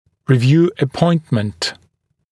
[rɪ’vjuː ə’pɔɪntmənt][ри’вйу: э’пойнтмэнт]повторное, последующее посещение пациента для осмотра